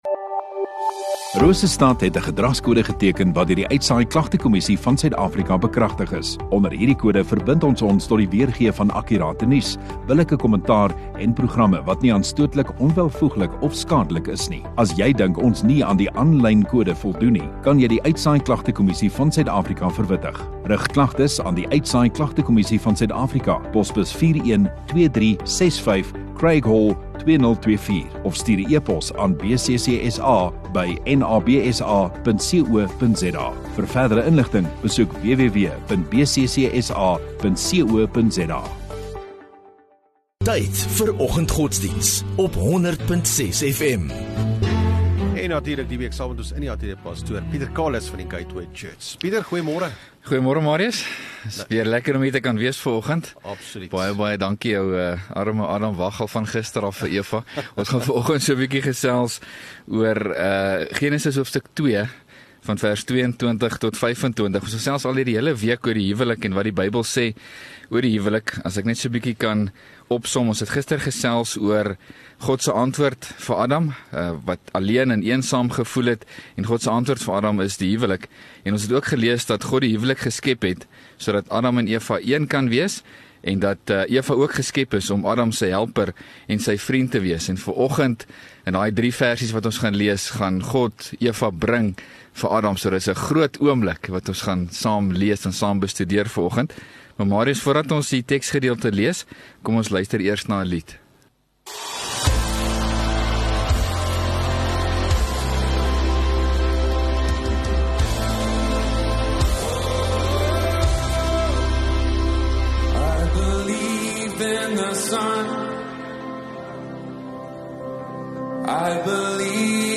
13 Nov Woensdag Oggenddiens